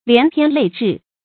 连篇累帙 lián piān lèi zhì
连篇累帙发音
成语注音 ㄌㄧㄢˊ ㄆㄧㄢ ㄌㄟˋ ㄓㄧˋ